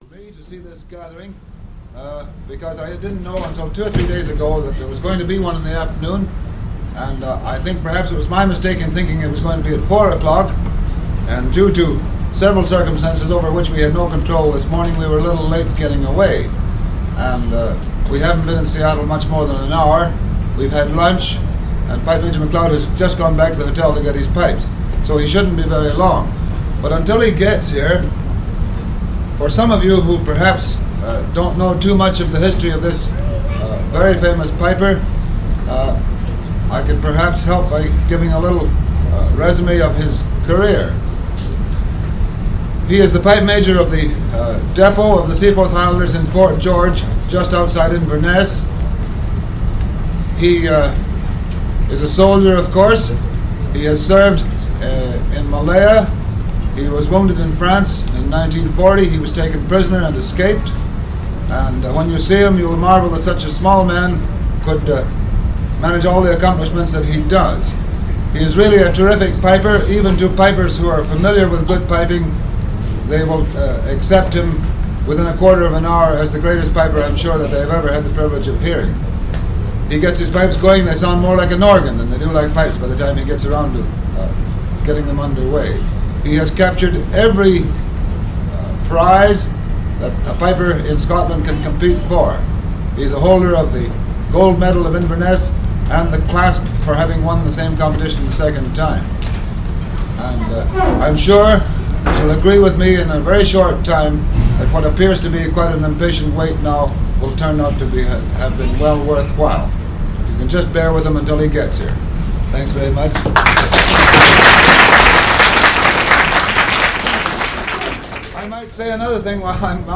It was made in Seattle, WA, in 1955 whilst Donald was on a tour of the western US and Canada.
As can be heard in the introduction, the recital was impromptu, so much so that Donald did not have time to don his kilt.
He begins with a Scott Skinner tune, the Cameron Quickstep, and strathspeys and reels.
The music follows the introductions about half way through the first excerpt: